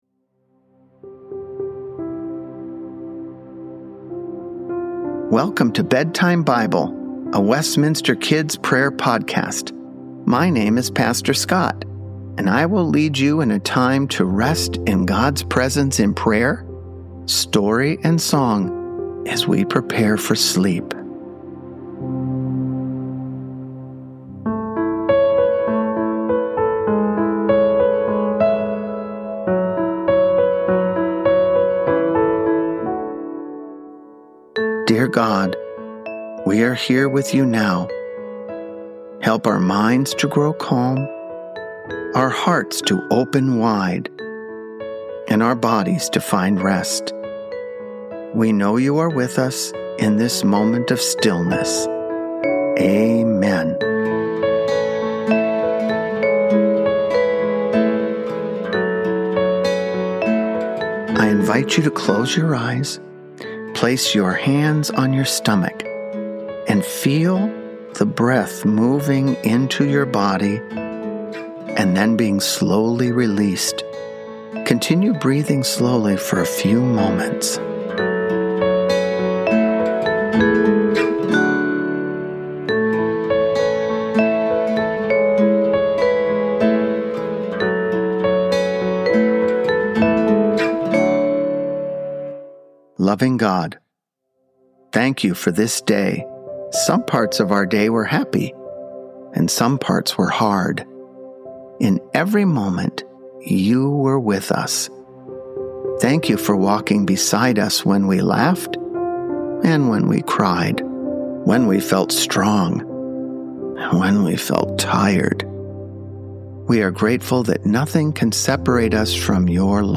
Evening Song - "All Through the Night" - words by Sir Harold Boulton and the music is a traditional Welsh folk tune.
Help your children prepare for bed with our prayer and relaxation podcast.  Each podcast will feature calming music, Scripture and prayers to help children unwind from their day.